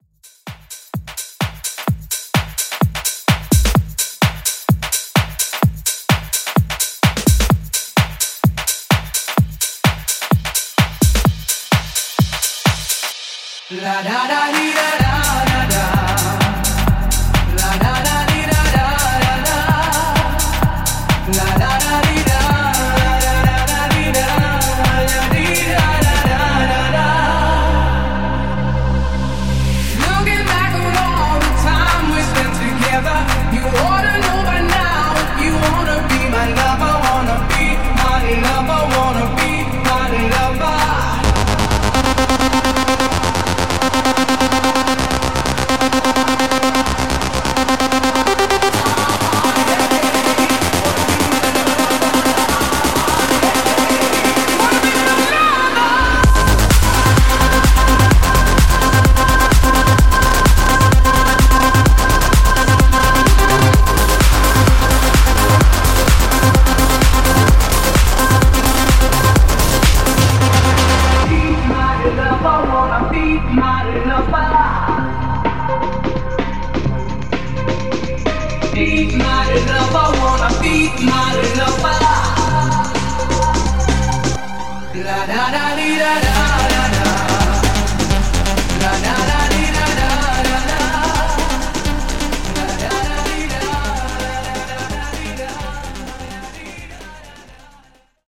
In-Outro Club Edit)Date Added